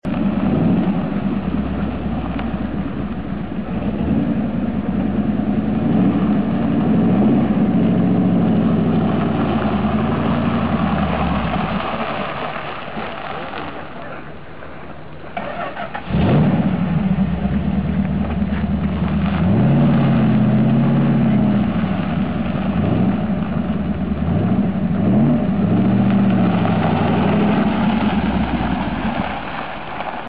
Monteverdi Hai 450 SS (1970) Motorensound